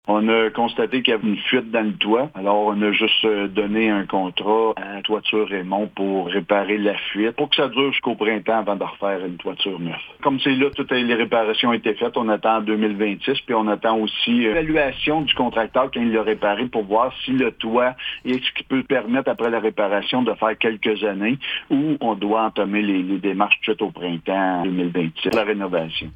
Le maire de Bouchette, Steve Lefebvre, explique les raisons qui ont motivé la réparation temporaire de la toiture de la caserne incendie :